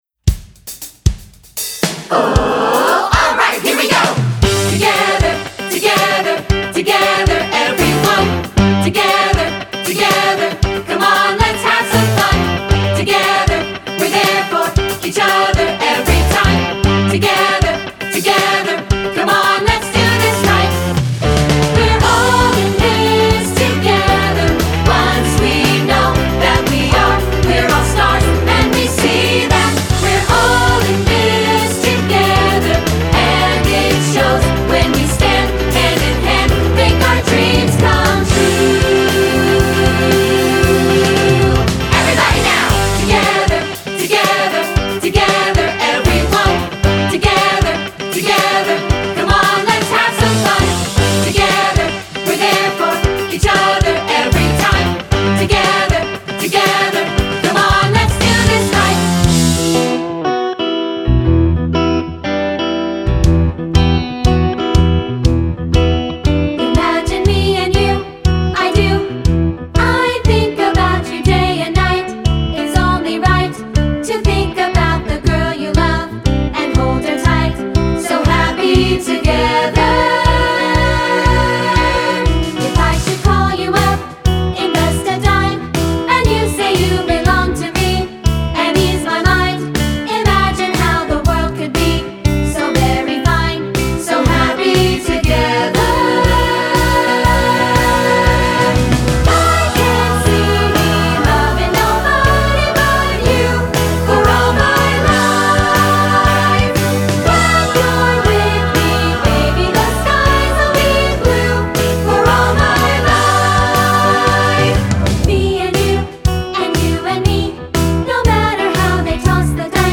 Choral Movie/TV/Broadway 70s-80s-90s Pop
3 Part Mix
3-Part Mixed Audio